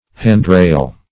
handrail \hand"rail`\ n.